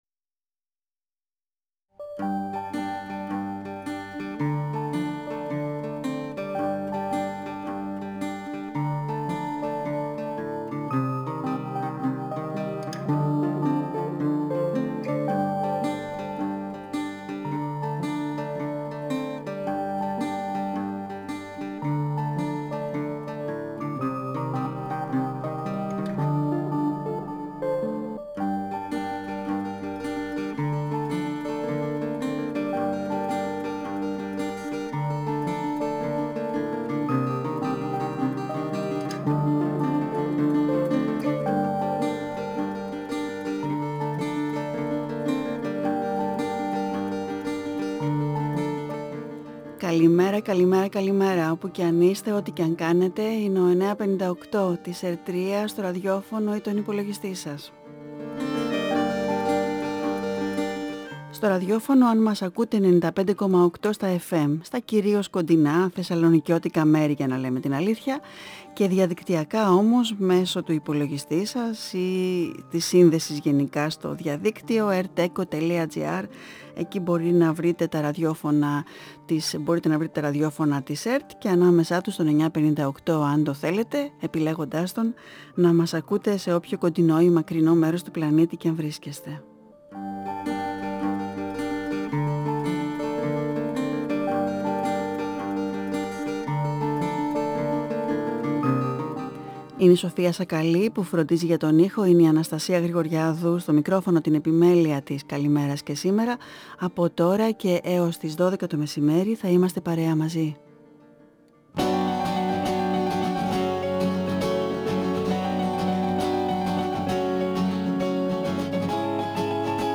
Συνέντευξη
Παρουσίαση του βιβλίου στην Θεσσαλονίκη: Παρασκευή 30.9.22 στο καφέ Ανφάν Γκατέ, Τελλόγλειο Η συνέντευξη πραγματοποιήθηκε την Πέμπτη 29 Σεπτεμβρίου 2022 στην εκπομπή “Καλημέρα” στον 9,58fm της ΕΡΤ3.